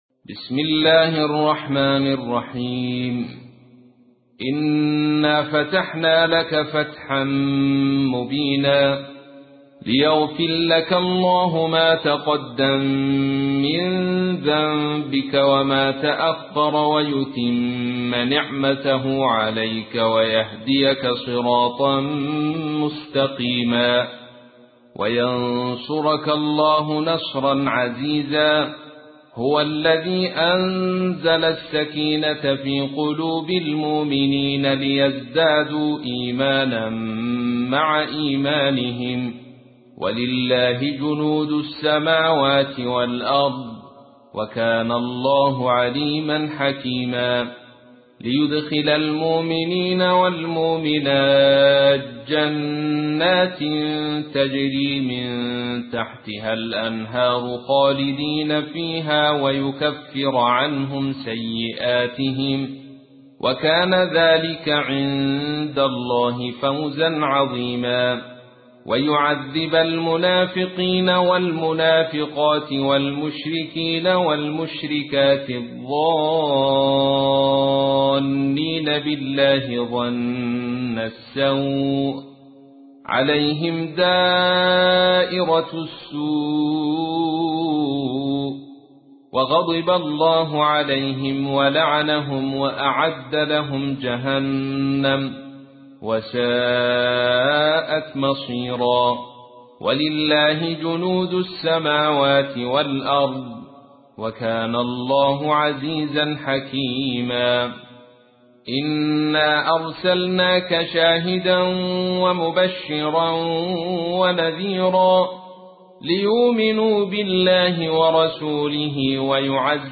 تحميل : 48. سورة الفتح / القارئ عبد الرشيد صوفي / القرآن الكريم / موقع يا حسين